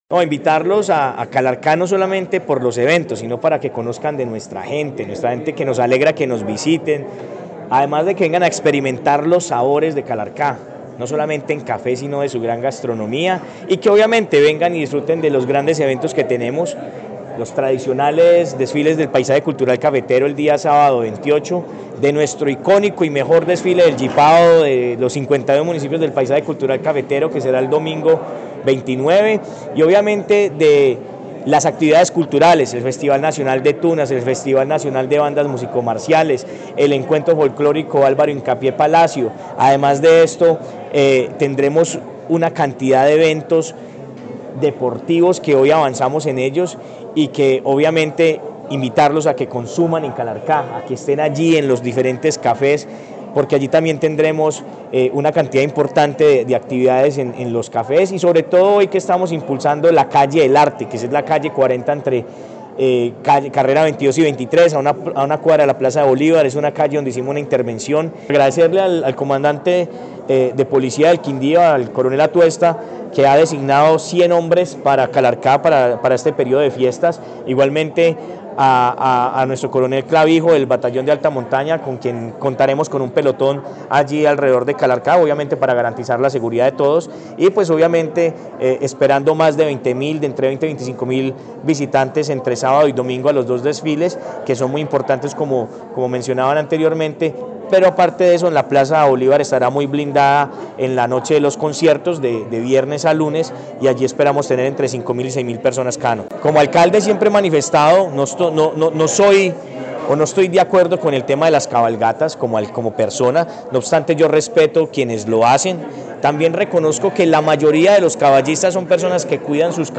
Alcalde de Calarcá, Sebastián Ramos